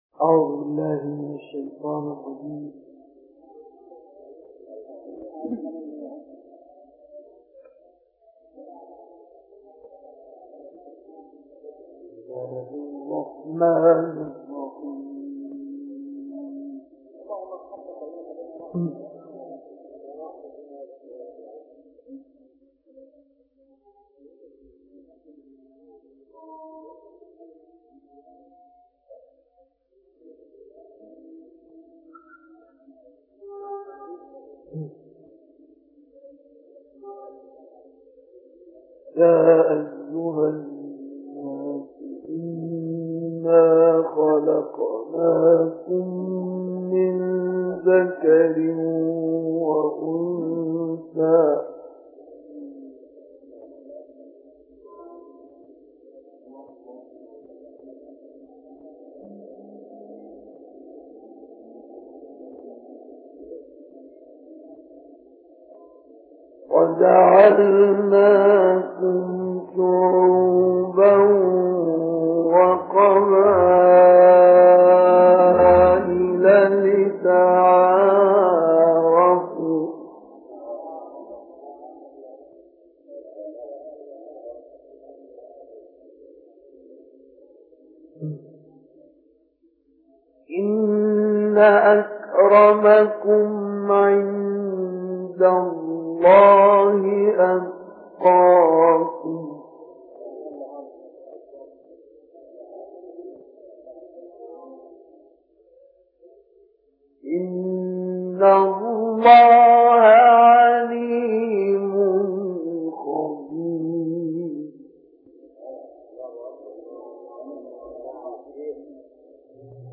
Surah Al-Hujurat, Surah Q, Al-Haqqa and Al-Nazi’at, Bab El Khalk, 1962
Quran recitations